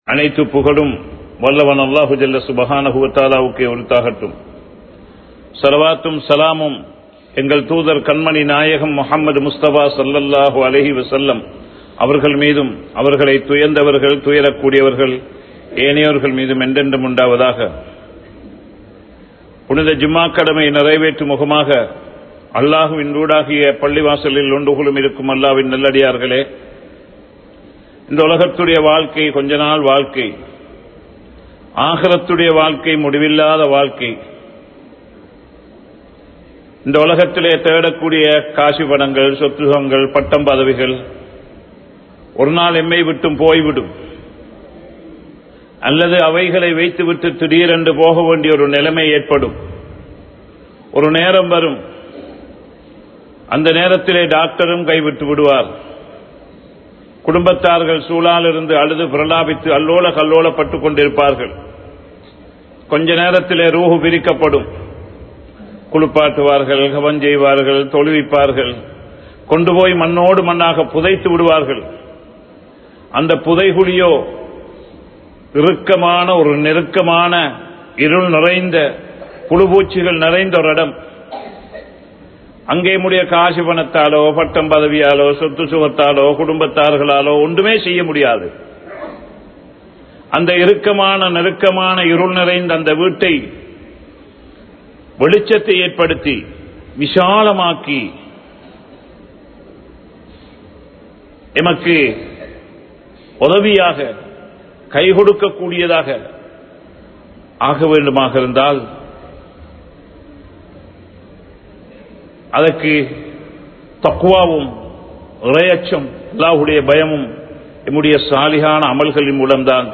Masjidh Nirvaahihalin Thahaimaihal (மஸ்ஜித் நிர்வாகிகளின் தகைமைகள்) | Audio Bayans | All Ceylon Muslim Youth Community | Addalaichenai
Watadeniya Jumua Masjidh